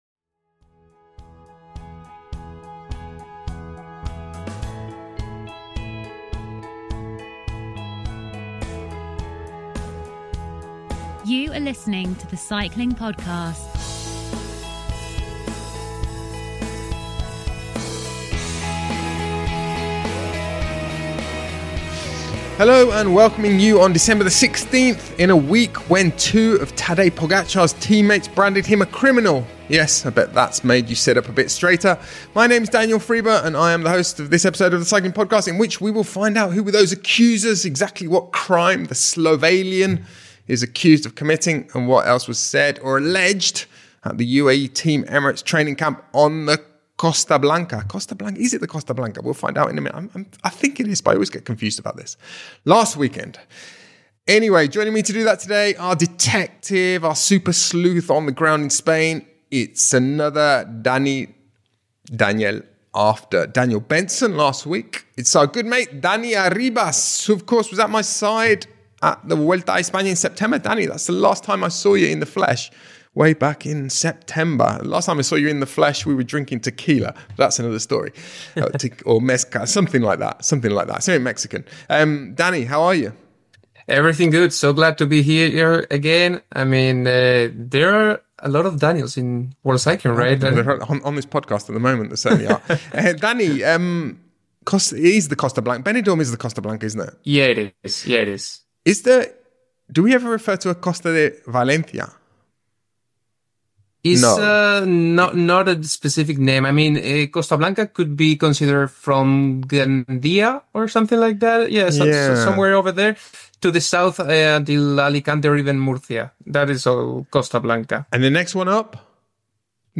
Before hearing Tadej Pogačar reveal his 2026 race programme in Benidorm, we find out more about a revamped and renamed team born in Barcelona, NSN Cycling Team.